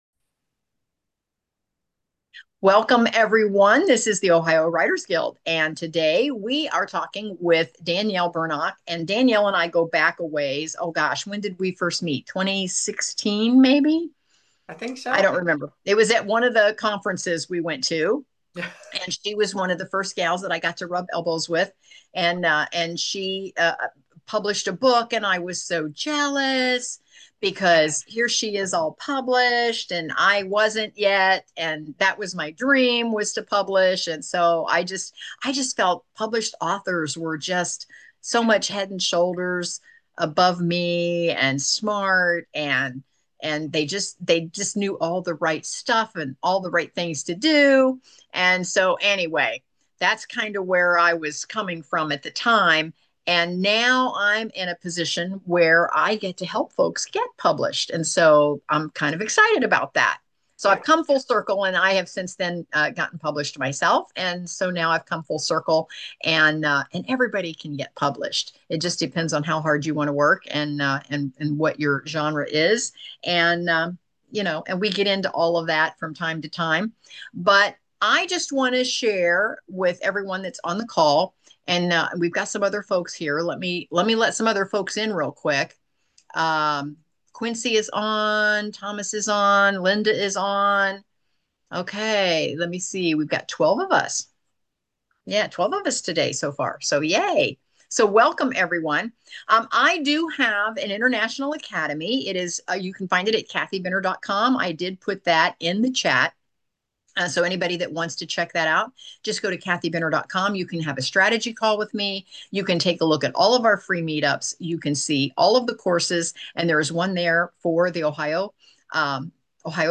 In this candid interview